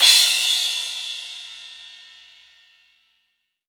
• Long Room Reverb Crash Cymbal Audio Clip C Key 14.wav
Royality free crash one shot tuned to the C note. Loudest frequency: 4446Hz
long-room-reverb-crash-cymbal-audio-clip-c-key-14-nDP.wav